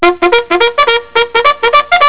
The two sounds in the parenthesis are fire button sounds.
Bugle, Whinny)
bugle.wav